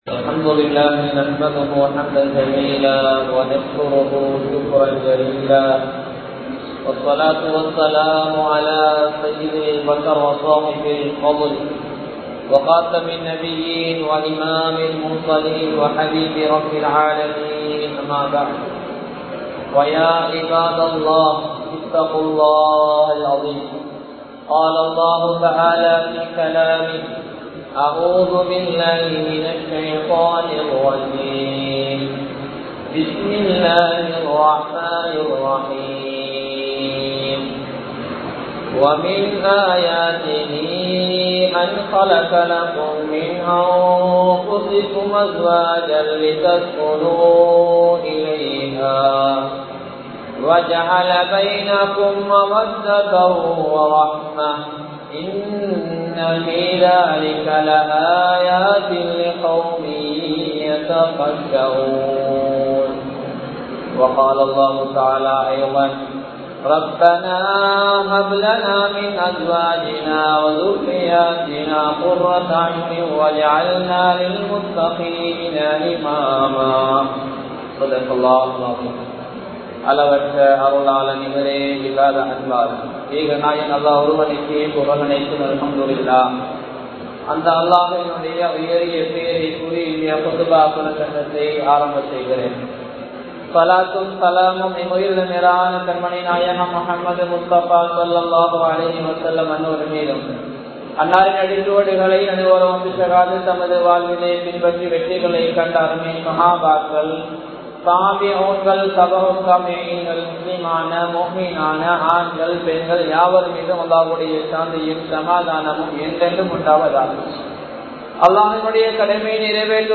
மகிழ்ச்சியான குடும்ப வாழ்க்கை | Audio Bayans | All Ceylon Muslim Youth Community | Addalaichenai